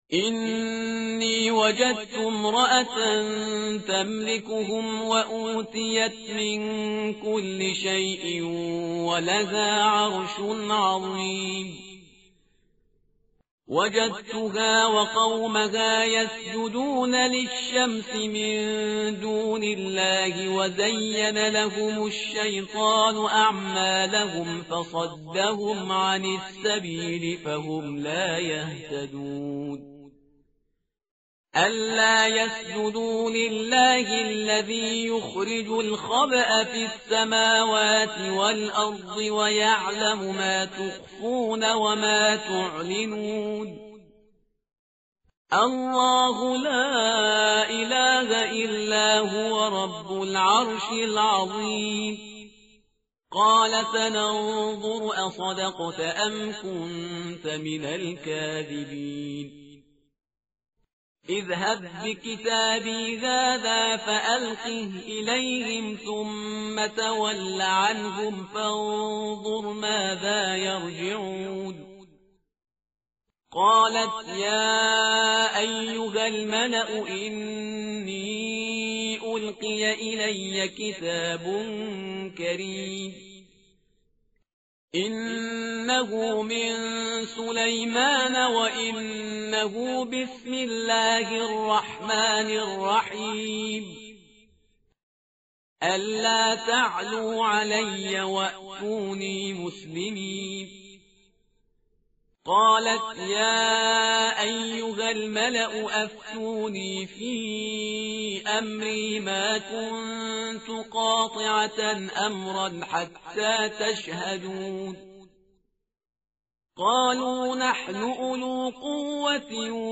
متن قرآن همراه باتلاوت قرآن و ترجمه
tartil_parhizgar_page_379.mp3